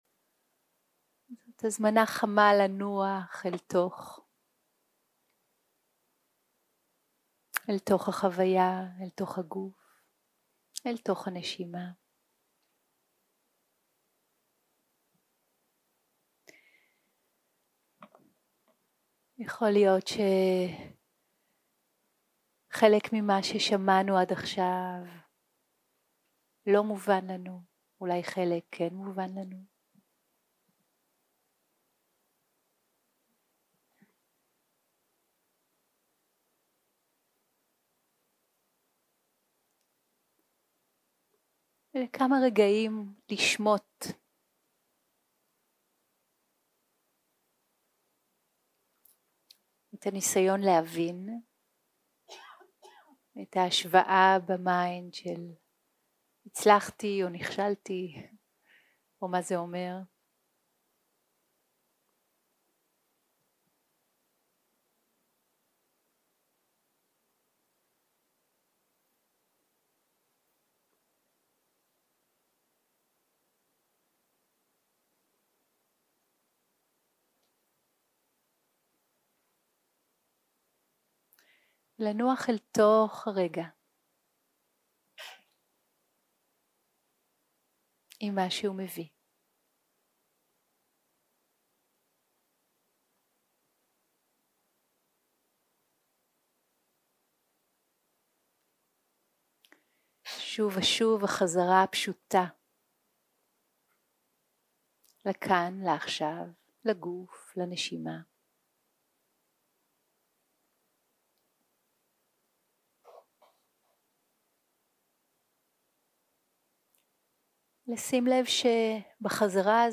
יום 4 - הקלטה 16 - בוהריים - מדיטציה מונחית + שאלות ותשובות
סוג ההקלטה: סוג ההקלטה: מדיטציה מונחית